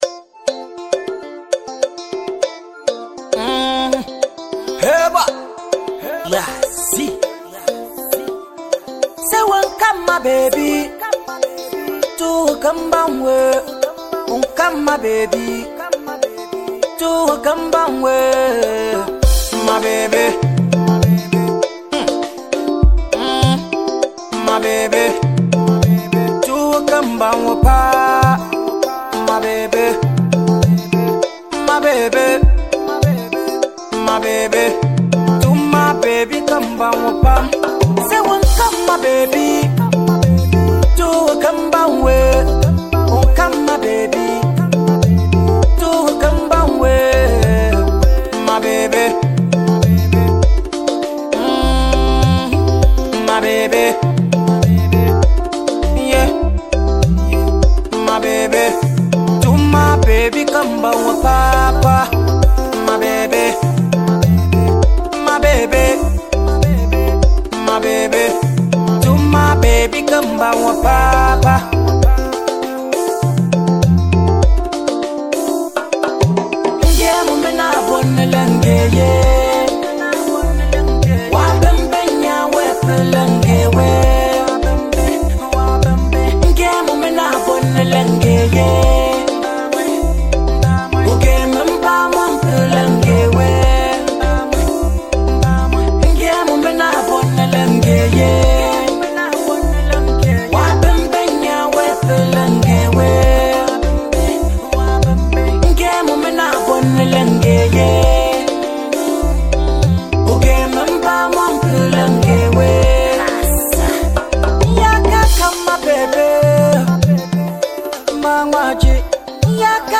With its infectious rhythm and soulful vibe
Most of his songs are Afrobeat and Dancehall.